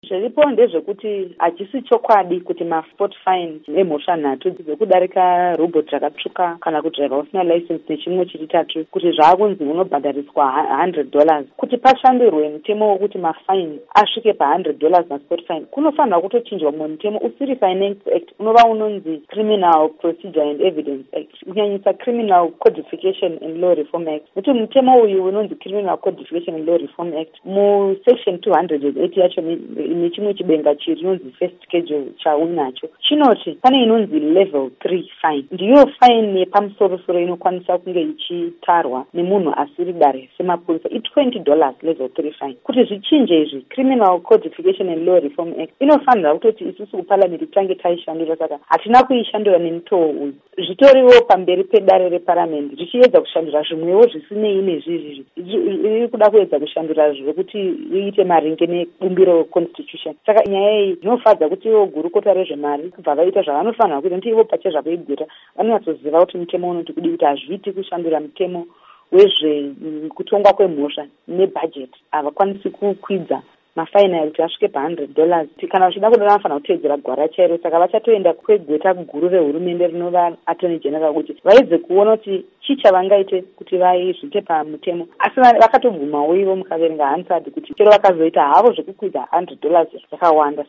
Hurukuro NaMuzvare Jessie Majome